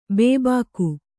♪ bd`bāku